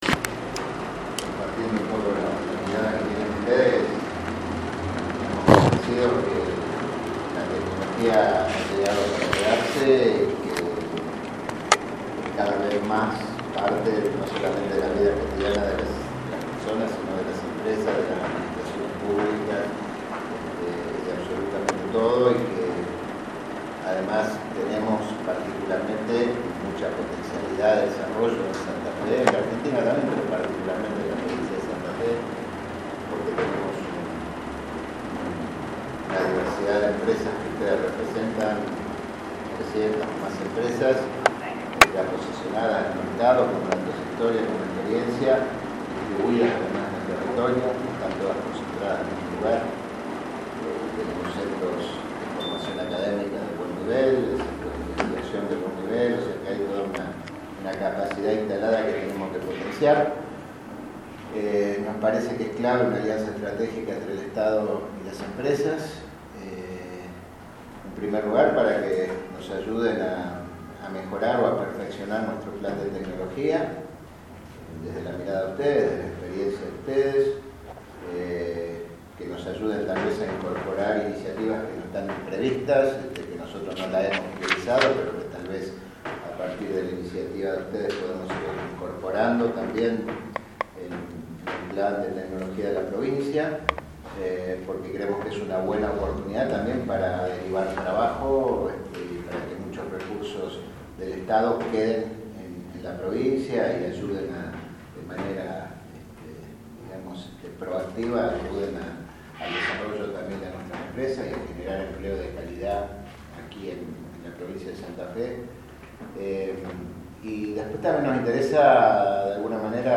El gobernador Miguel Lifschitz firmó este viernes, en Rosario, un convenio de cooperación con entidades intermedias de Tecnologías de la Información y las Comunicaciones (TICs), con el fin de avanzar en la ejecución de los distintos proyectos del Plan Estratégico de Tecnologías “TecnoFE”.
Palabras del gobernador Miguel Lifschitz